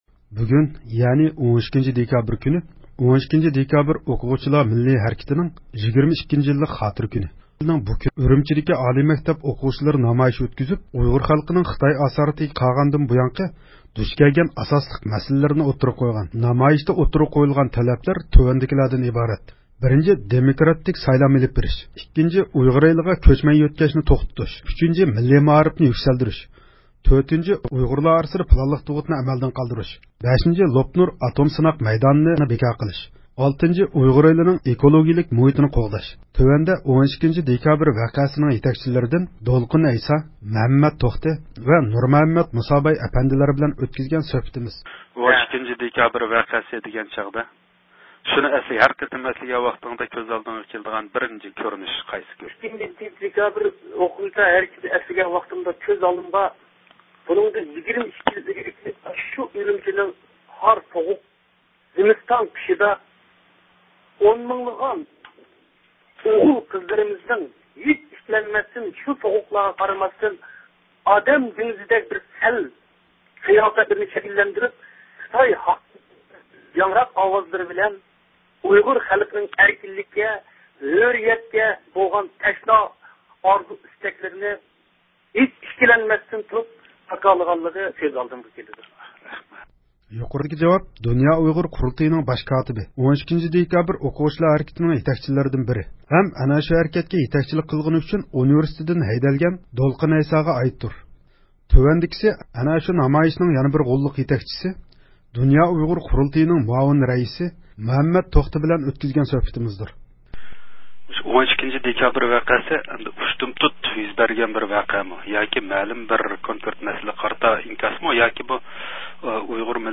12 - دېكابىر ئوقۇغۇچىلار ھەرىكىتى ھەققىدە سۆھبەت – ئۇيغۇر مىللى ھەركىتى